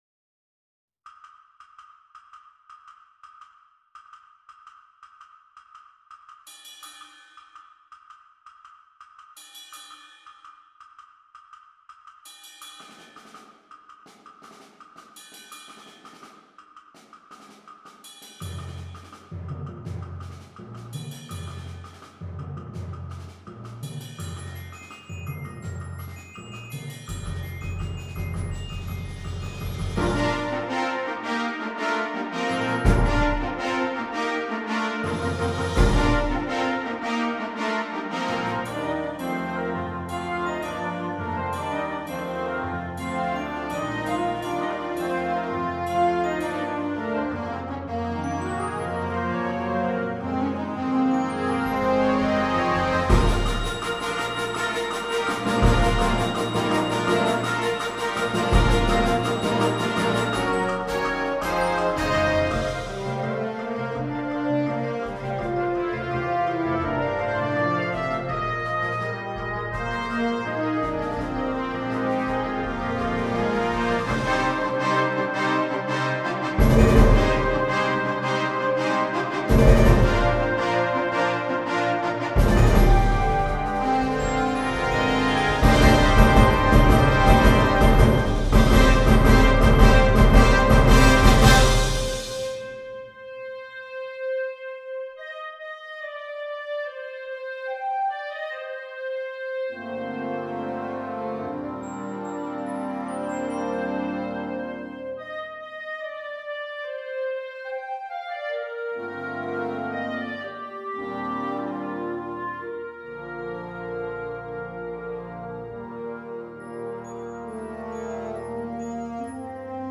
Gattung: Jugendwerk
Besetzung: Blasorchester